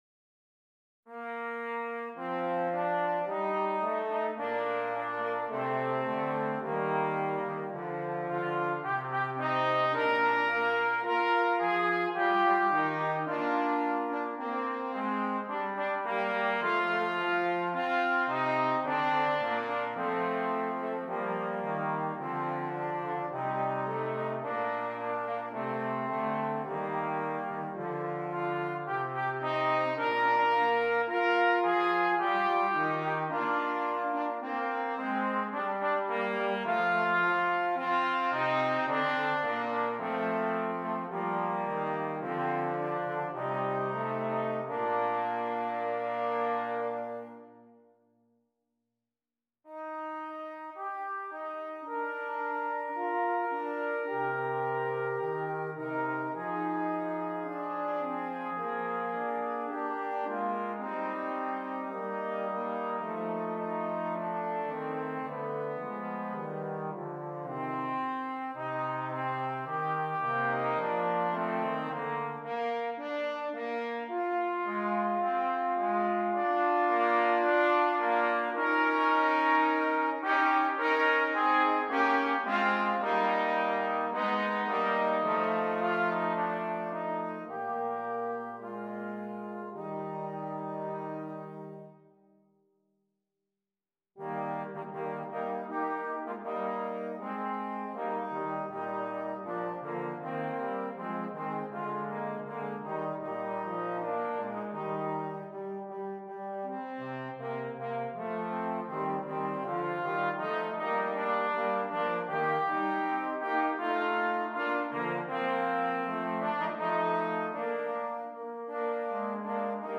Brass Trio
beginning brass trio
This is another excellent set of arrangements for brass.